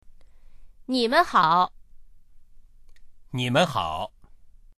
nimenhao.mp3